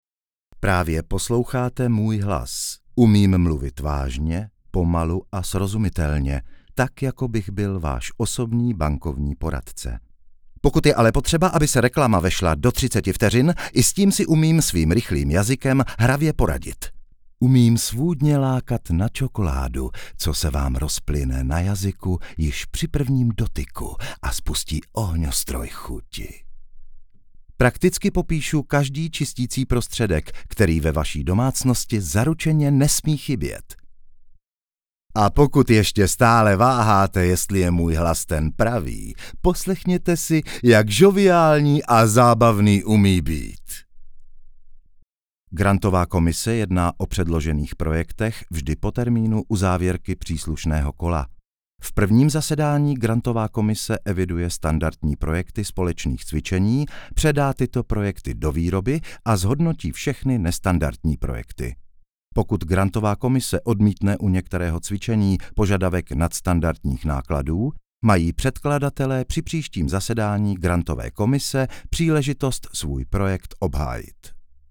Audioknihy:
Povolání: herec, dabér, režisér českého znění